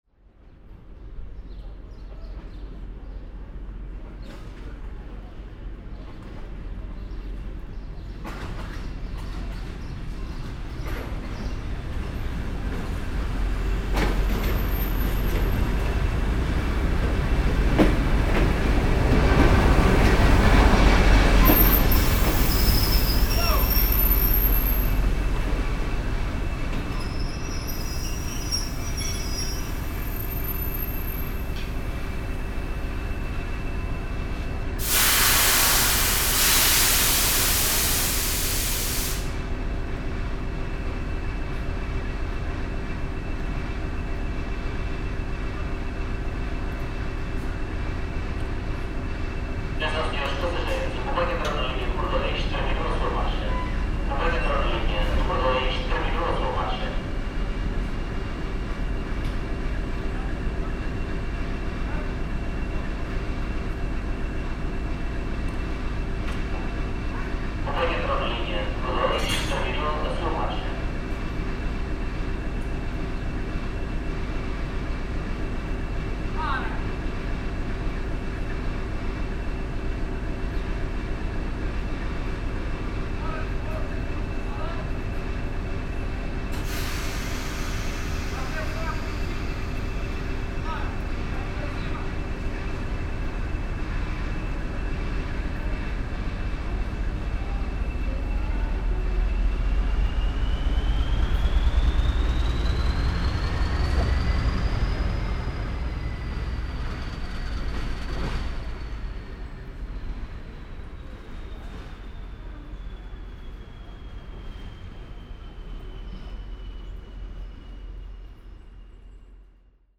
Gemafreie Sounds: Bahnhof
mf_SE-8330-train_stops_at_a_signal.mp3